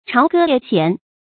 朝歌夜弦 zhāo gē yè xián
朝歌夜弦发音